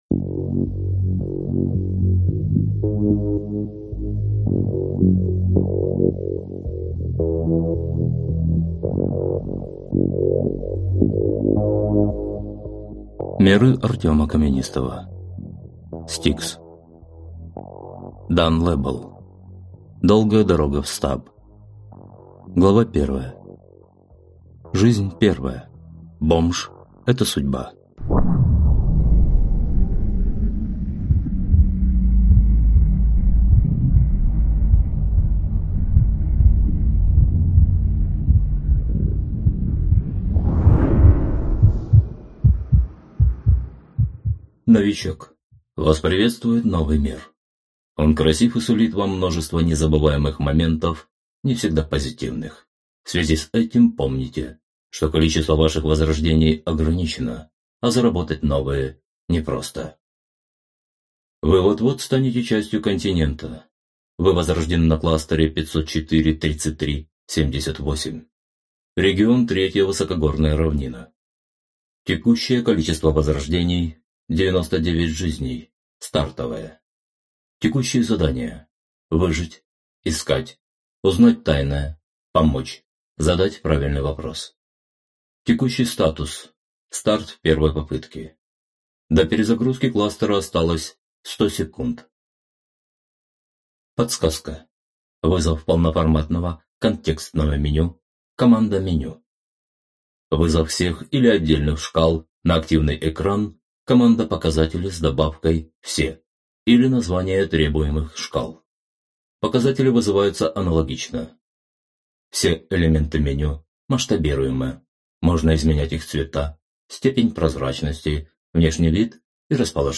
Аудиокнига S-T-I-K-S. Долгая дорога в стаб | Библиотека аудиокниг